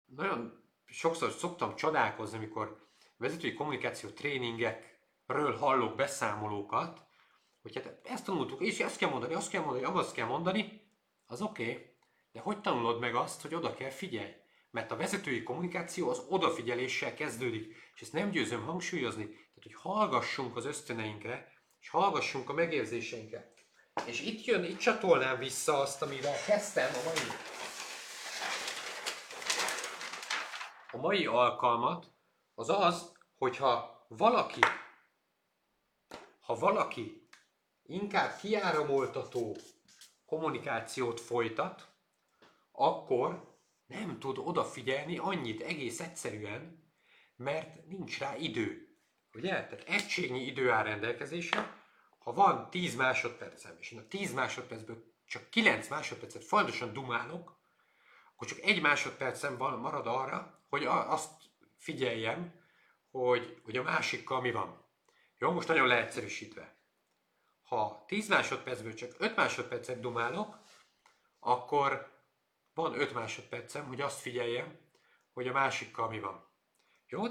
A rövid hanganyag segítségével most izelítőt kaphatsz a mesterkurzusból.
TGVA-Live-Motivalo-vezetoi-kommunikacio-mesterkurzus-1.-A-vezetoi-kommunikacio-titkos-csodafegyvere_rovid.mp3